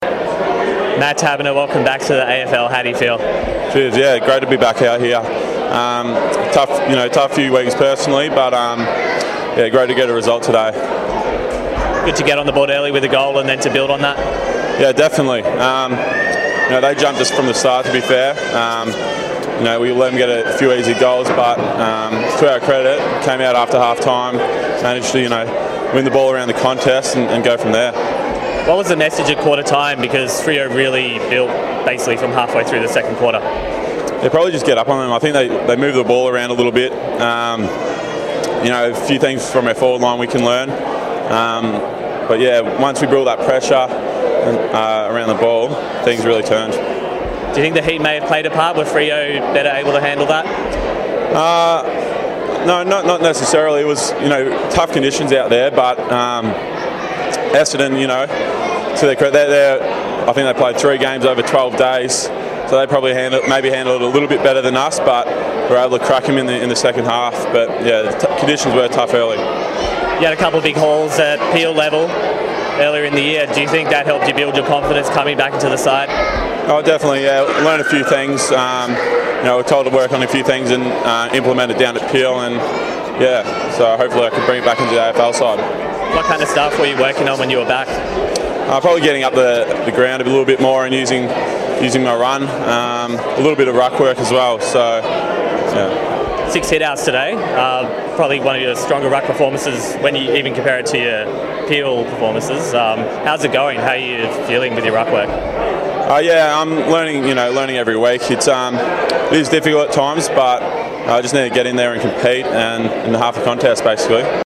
Matthew Taberner chats to Docker TV after Freo's win over the Bombers.